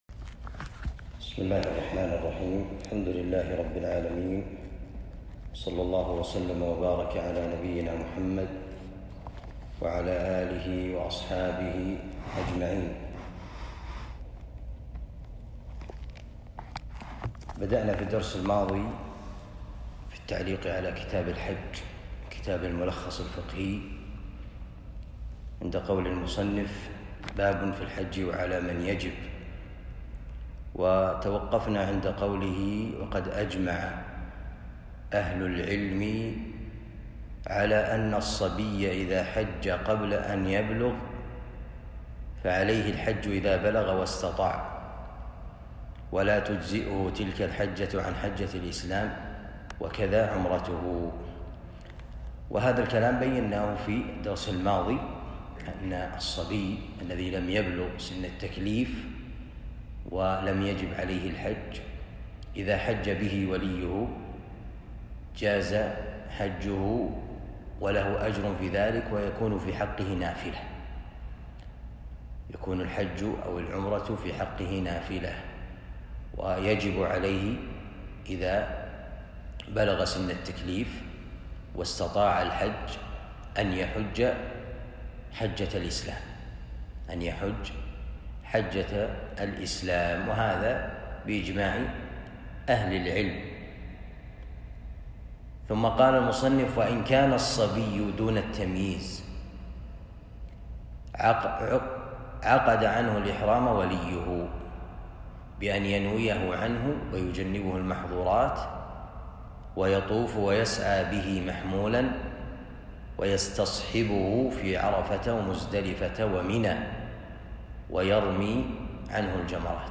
الدرس الثاني من كتاب الحج باب في الحج وعلى من يجب من كتاب الملخص الفقهي